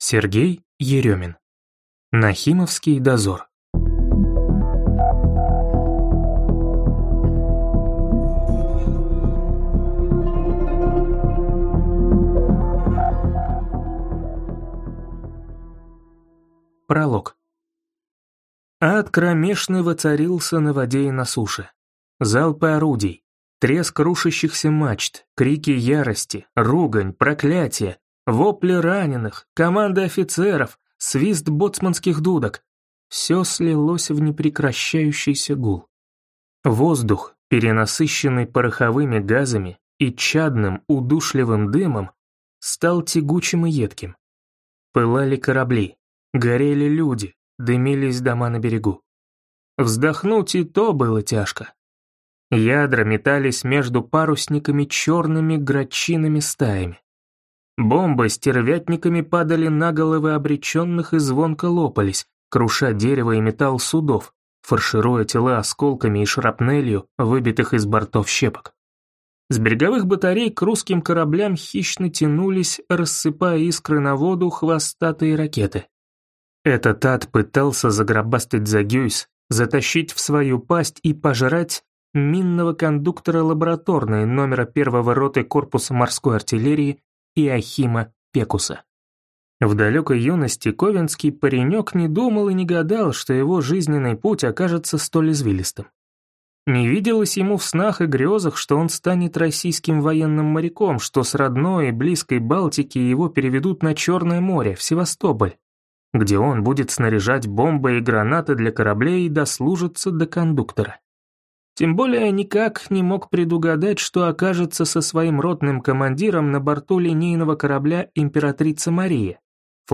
Аудиокнига Нахимовский Дозор | Библиотека аудиокниг